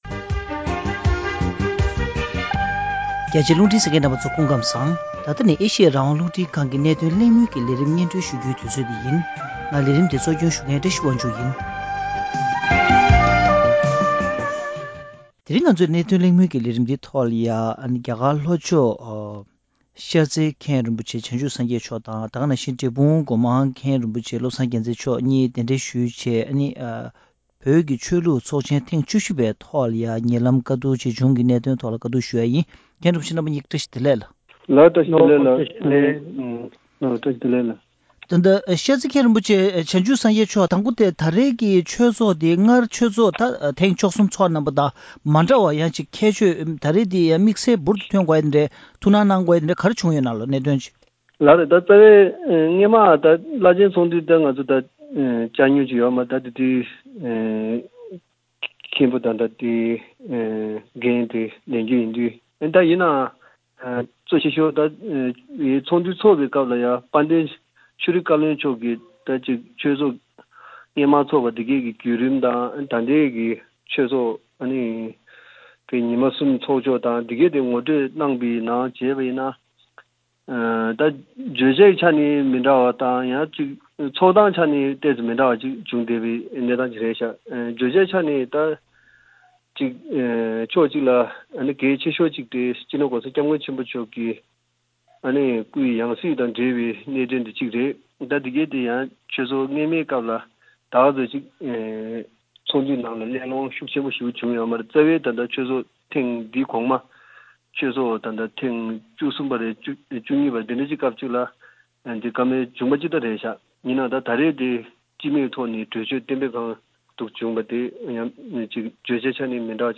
གནད་དོན་གླེང་མོལ་གྱི་ལས་རིམ་ནང་།